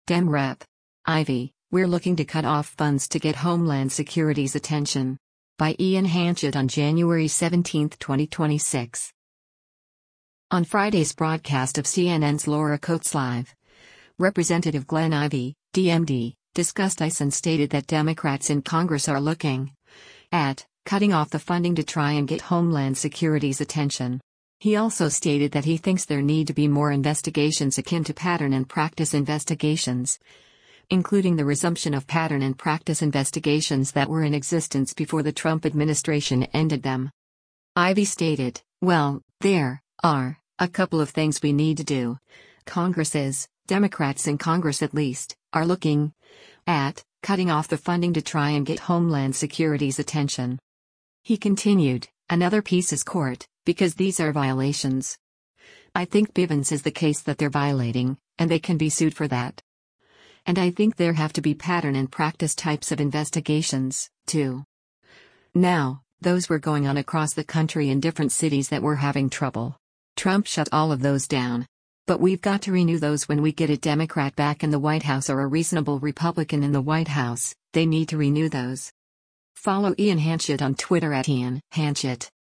On Friday’s broadcast of CNN’s “Laura Coates Live,” Rep. Glenn Ivey (D-MD) discussed ICE and stated that Democrats in Congress “are looking [at] cutting off the funding to try and get Homeland Security’s attention.”